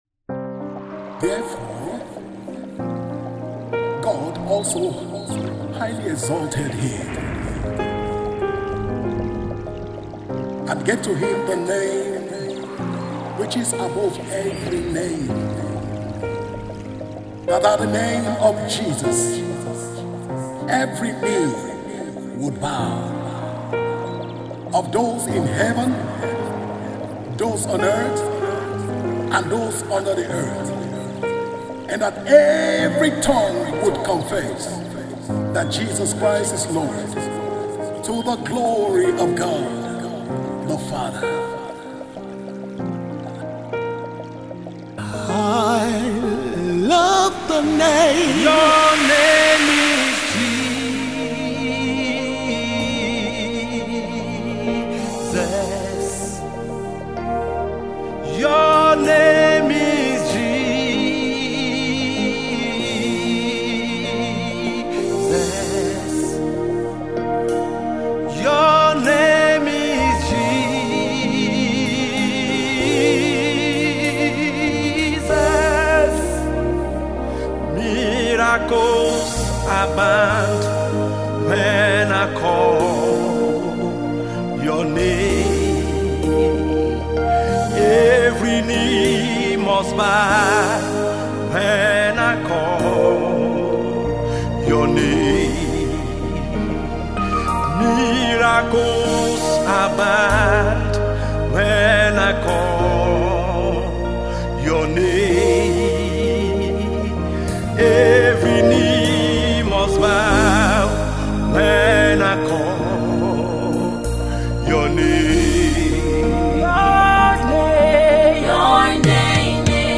spirit-filled song
The song is produced by the ace gospel music producer